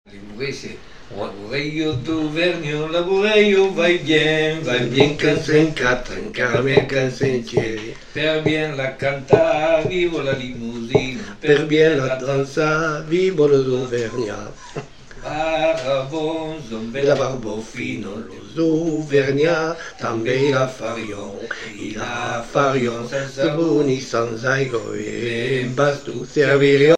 Couplets à danser
danse : bourree